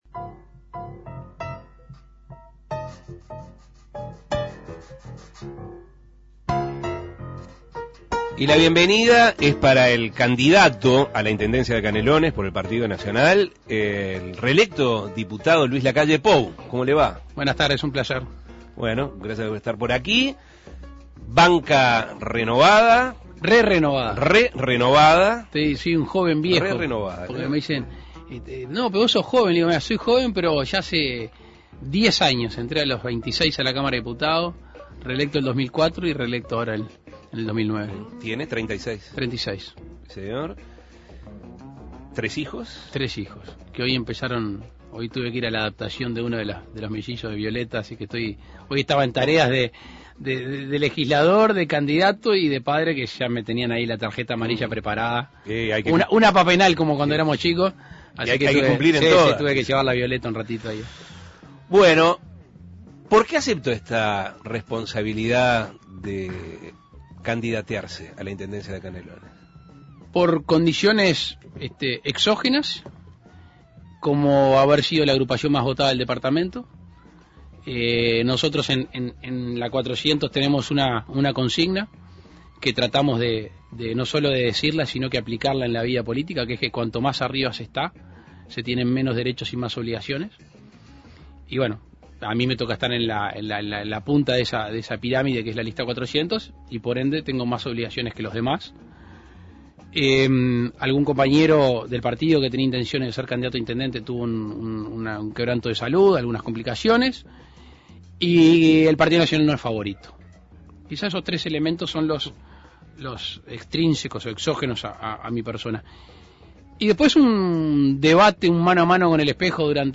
Dialogó sobre propuestas que buscan ayudar a ese departamento y a sus habitantes, cómo surgió su candidatura y sobre similitudes y diferencias con la campaña del candidato del Frente Amplio, Marcos Carámbula. Escuche la entrevista.